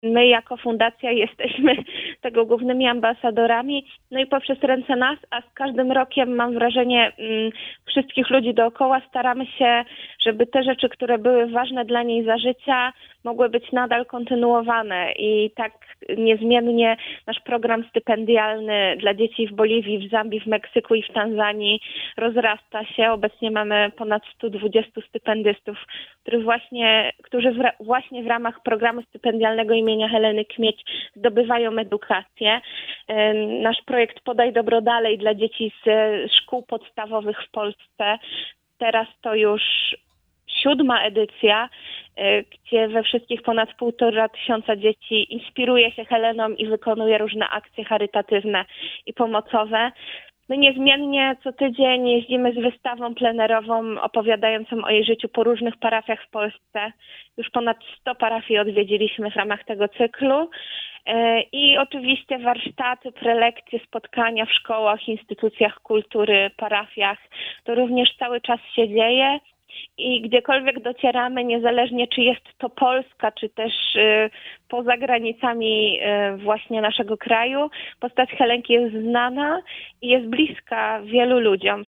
Łączymy się telefonicznie